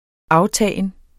Udtale [ ˈɑwˌtæˀjən ]